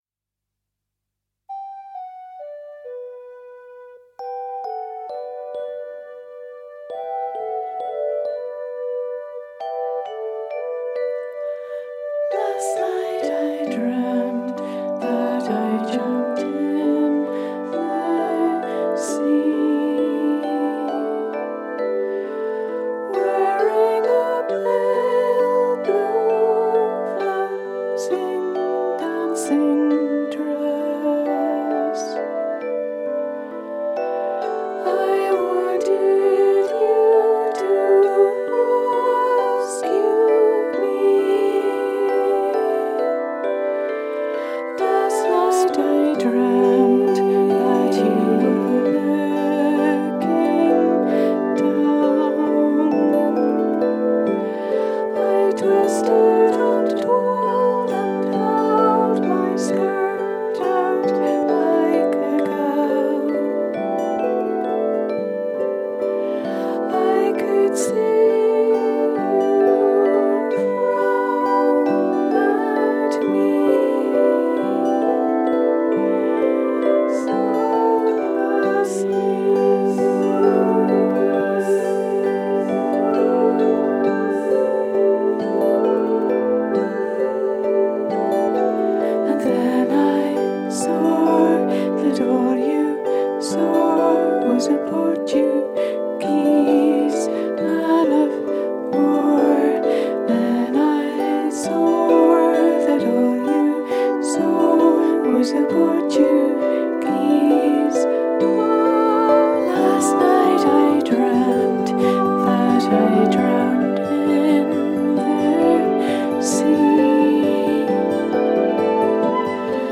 暖かく柔らかく神秘的な歌の世界が、聴き手に極上のひと時を与えてくれることを約束します！